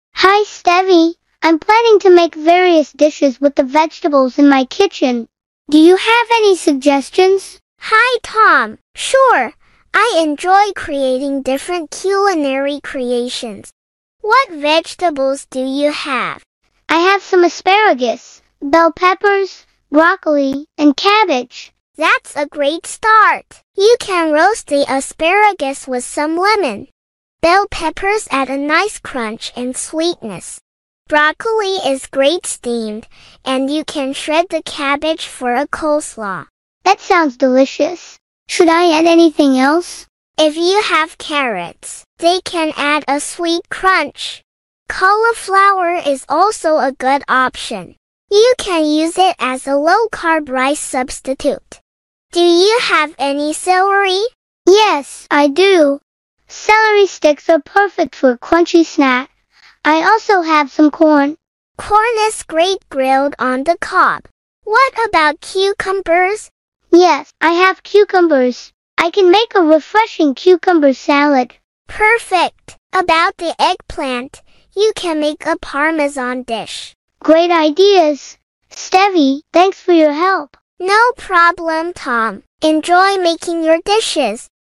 Dialogue 1
Dialog-1-Vegetables.mp3